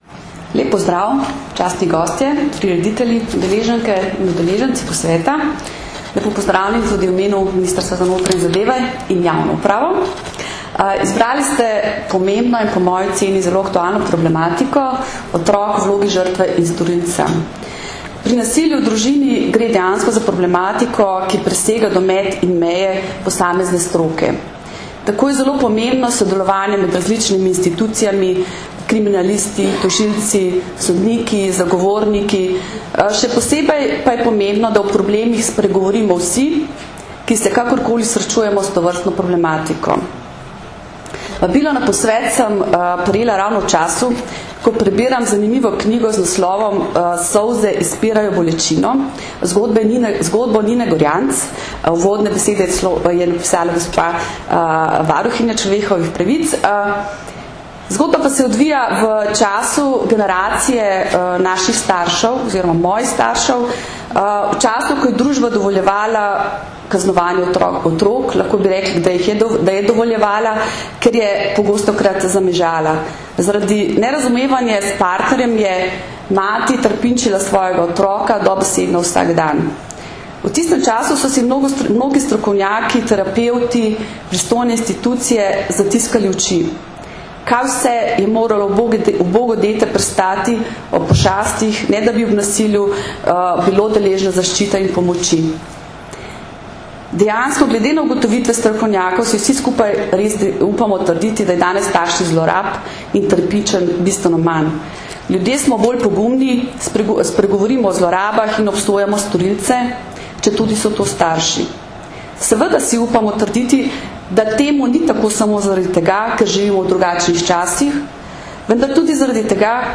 Na Brdu pri Kranju se je danes, 4. aprila 2013, začel dvodnevni strokovni posvet z naslovom Otrok v vlogi žrtve in storilca, ki ga že dvanajsto leto zapored organizirata Generalna policijska uprava in Društvo državnih tožilcev Slovenije v sodelovanju s Centrom za izobraževanje v pravosodju.
Zvočni posnetek nagovora državne sekretarke Ministrstva za notranje zadeve in javno upravo mag. Renate Zatler (mp3)